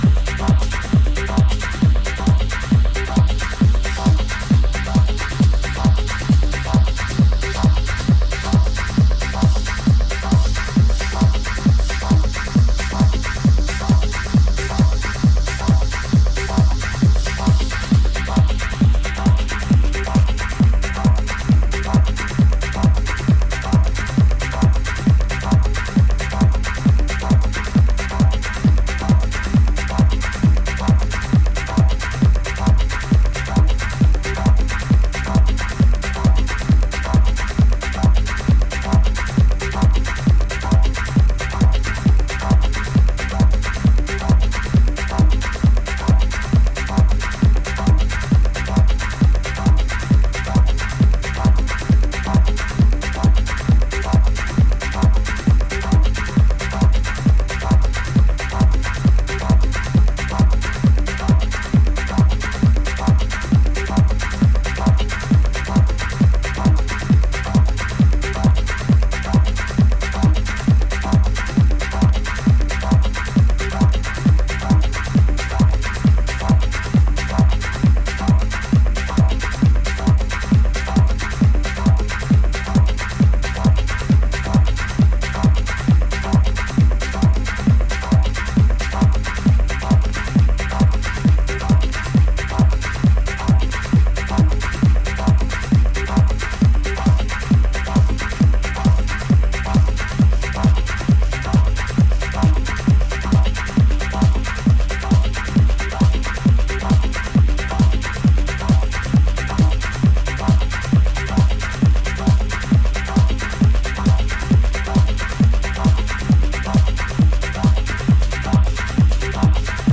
simple but effective minimalism for the dancefloor.
hypnotic sounds
Detroit Techno